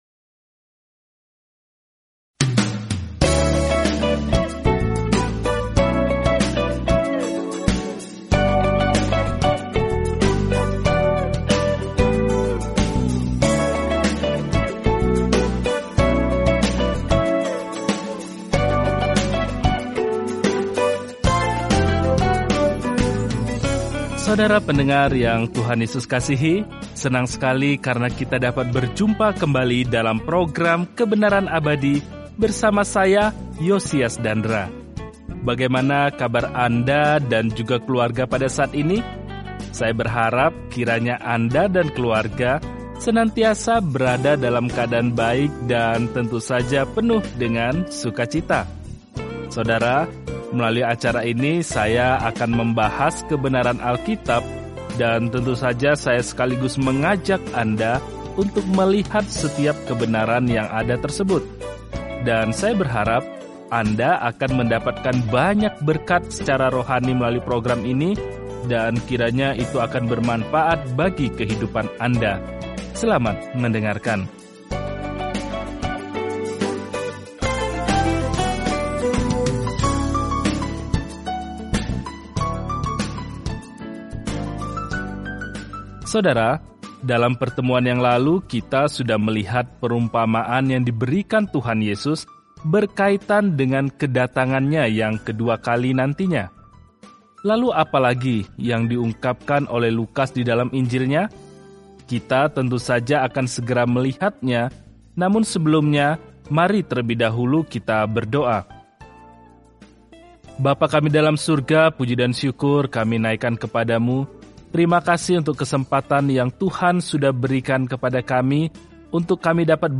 Firman Tuhan, Alkitab Lukas 13 Lukas 14:1-6 Hari 15 Mulai Rencana ini Hari 17 Tentang Rencana ini Para saksi mata menginformasikan kabar baik yang diceritakan Lukas tentang kisah Yesus sejak lahir, mati, hingga kebangkitan; Lukas juga menceritakan kembali ajaran-Nya yang mengubah dunia. Telusuri Lukas setiap hari sambil mendengarkan pelajaran audio dan membaca ayat-ayat tertentu dari firman Tuhan.